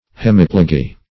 hemiplegy - definition of hemiplegy - synonyms, pronunciation, spelling from Free Dictionary Search Result for " hemiplegy" : The Collaborative International Dictionary of English v.0.48: Hemiplegy \Hem"i*ple`gy\, n. (Med.)